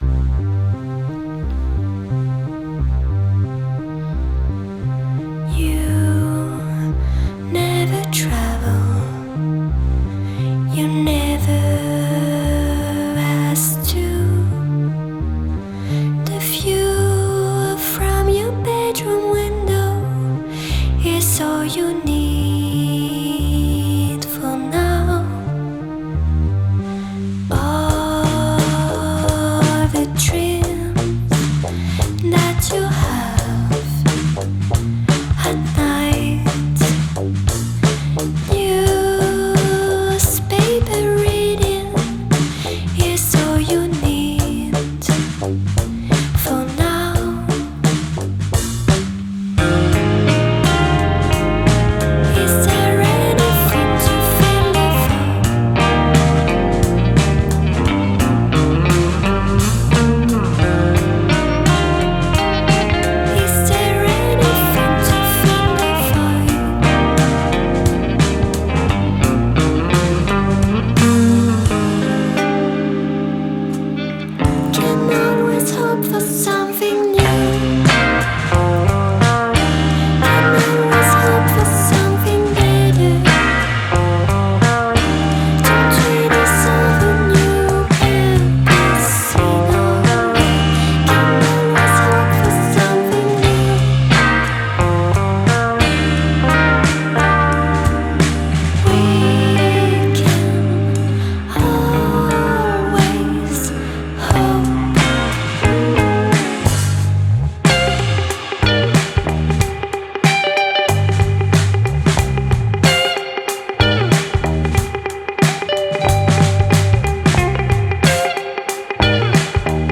The sultry, heady and hypnotic mixture.
live session